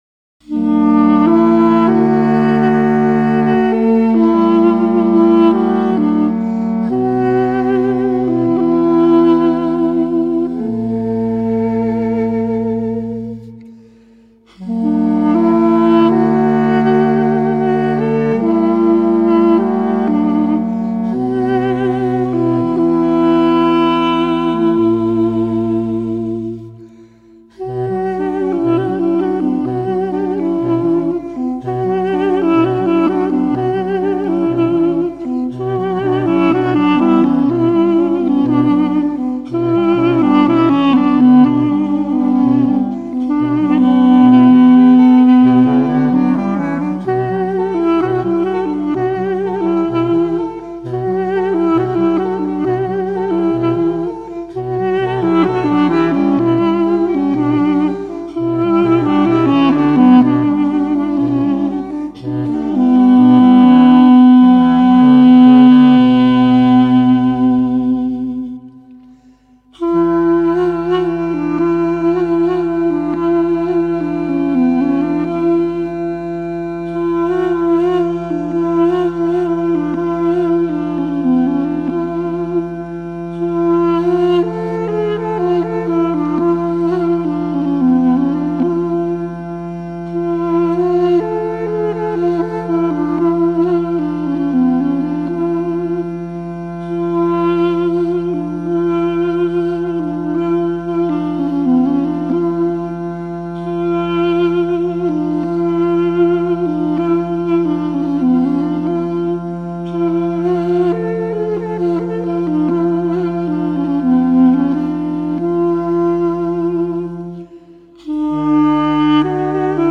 The warm, nasal tones of the duduk played by